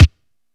• Crispy Kick One Shot E Key 114.wav
Royality free steel kick drum sample tuned to the E note. Loudest frequency: 536Hz
crispy-kick-one-shot-e-key-114-ekw.wav